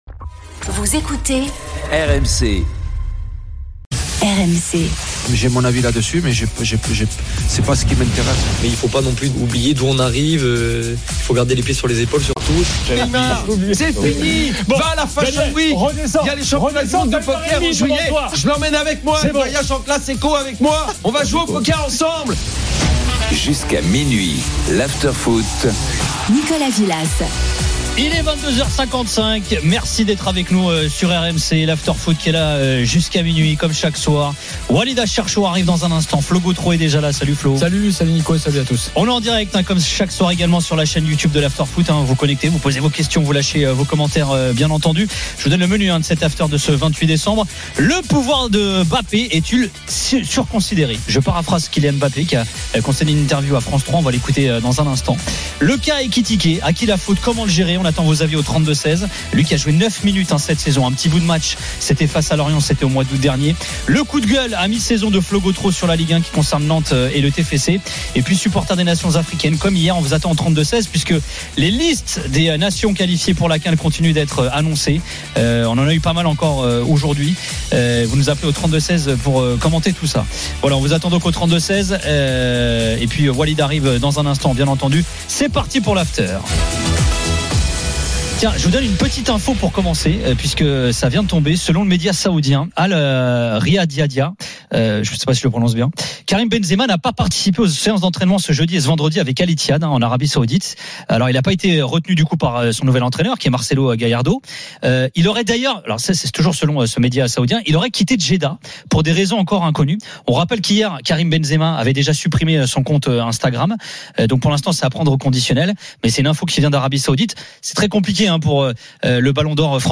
Chaque jour, écoutez le Best-of de l'Afterfoot, sur RMC la radio du Sport !
RMC est une radio généraliste, essentiellement axée sur l'actualité et sur l'interactivité avec les auditeurs, dans un format 100% parlé, inédit en France.